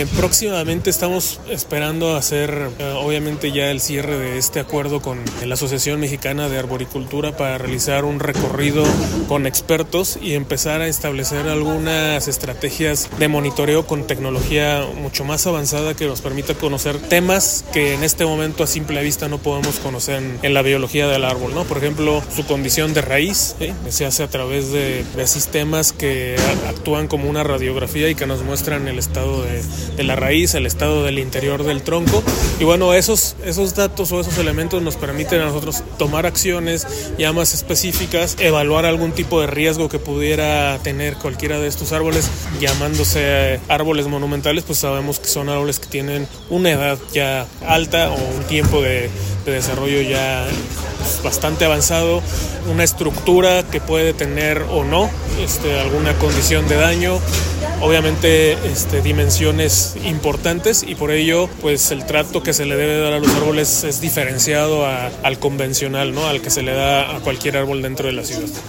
AudioBoletinesSustentabilidad